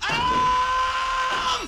alaaaaaaaarm.wav